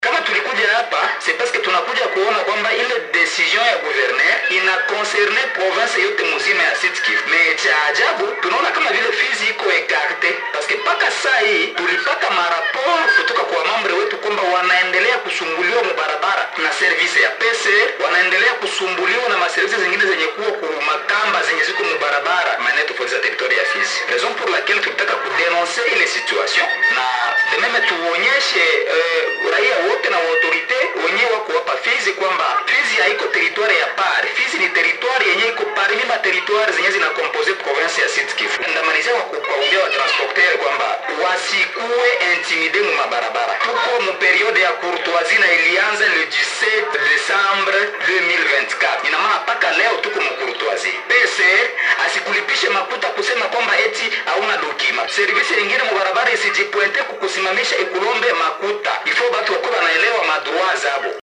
Dans un entretien avec Radio Maendeleo